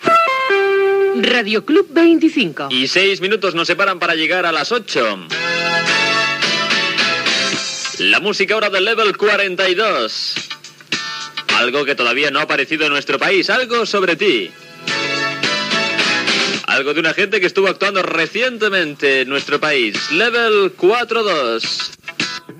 Indicatiu i tema musical